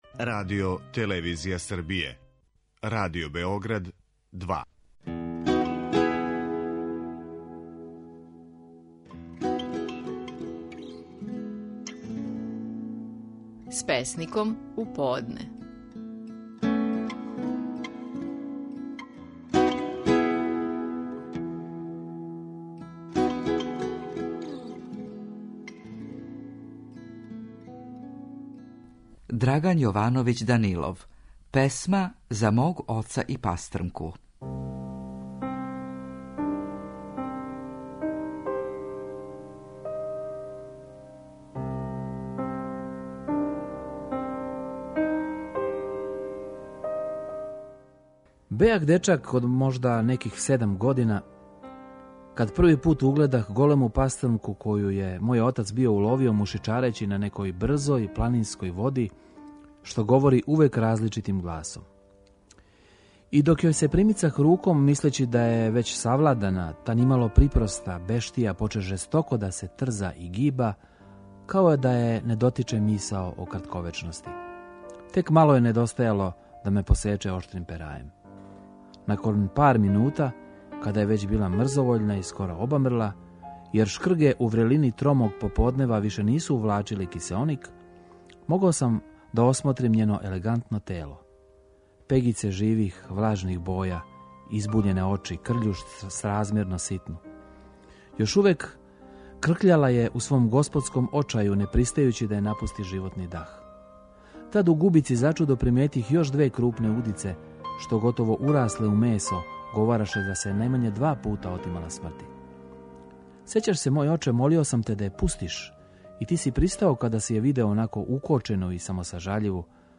Стихови наших најпознатијих песника, у интерпретацији аутора.
Драган Јовановић Данилов говори своју песму „Песма за мога оца и пастрмку".